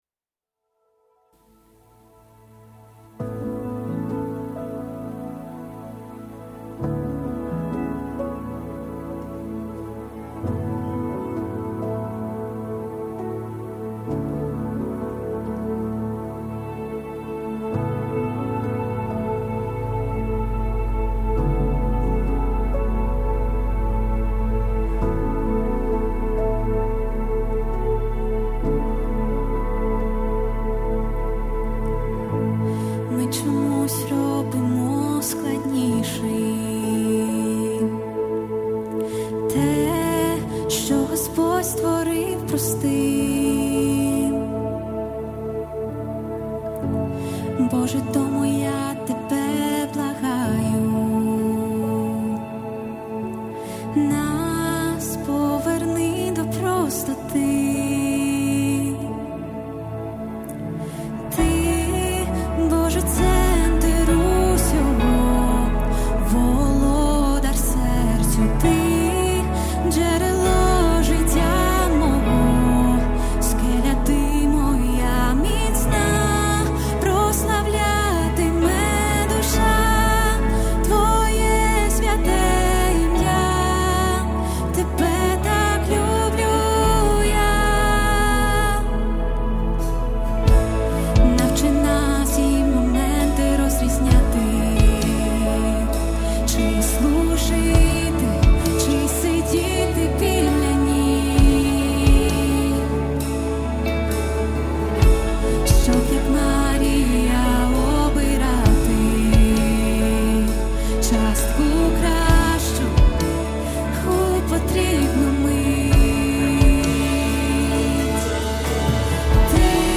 Worship Music
246 просмотров 215 прослушиваний 4 скачивания BPM: 132